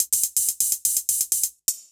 Index of /musicradar/ultimate-hihat-samples/125bpm
UHH_ElectroHatD_125-05.wav